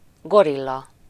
Ääntäminen
Synonyymit garde du corps goon armoire à glace Ääntäminen France: IPA: /ɡɔ.ʁij/ Haettu sana löytyi näillä lähdekielillä: ranska Käännös Ääninäyte 1. gorilla Suku: m .